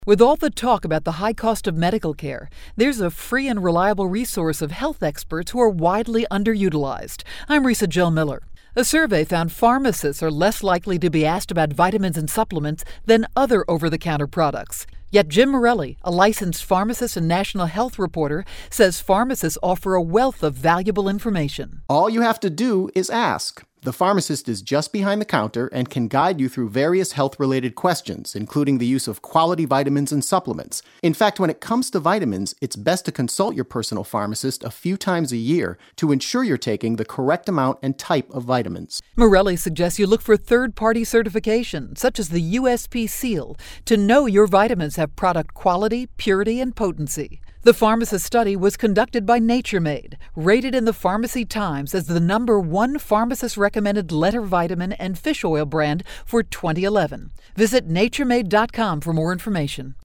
January 23, 2012Posted in: Audio News Release